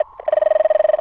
cartoon_electronic_computer_code_09.wav